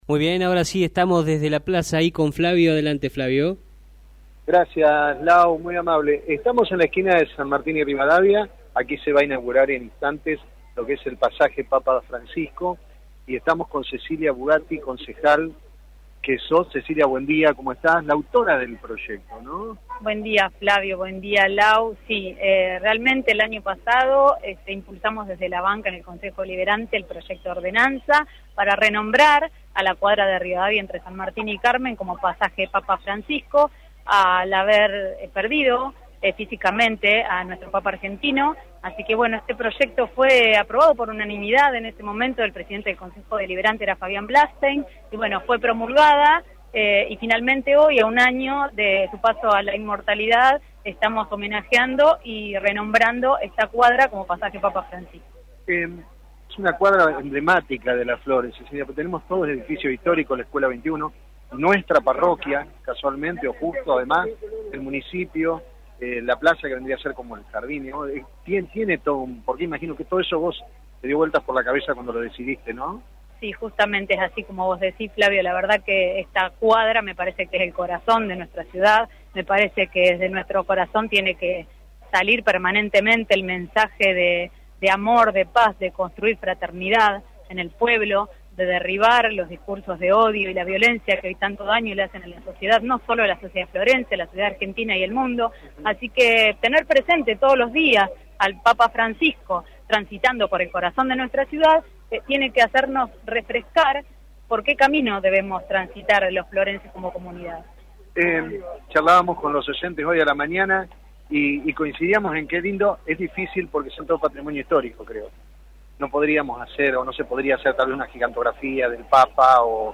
Previo al inicio del acto mantuvimos una charla con la autora del proyecto aprobado en el Concejo Deliberante Cecilia Bugatti.